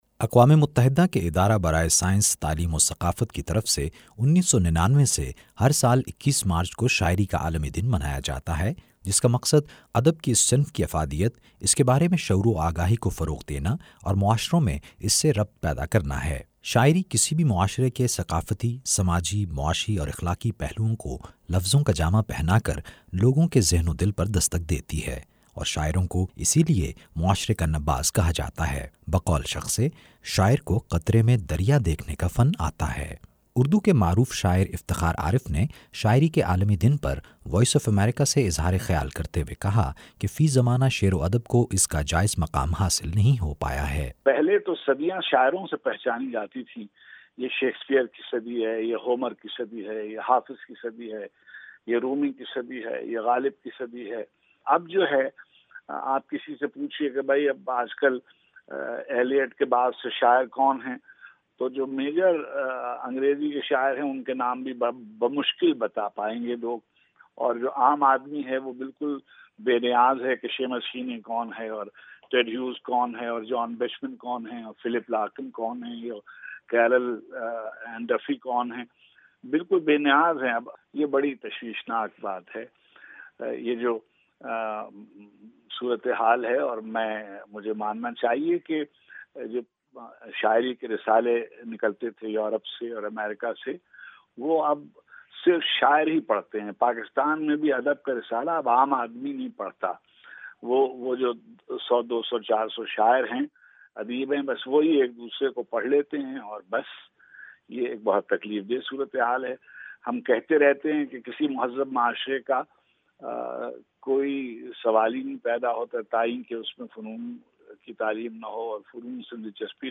شاعری کے عالمی دن پر خصوصی رپورٹ سنیے۔